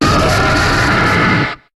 Cri de Charkos dans Pokémon HOME.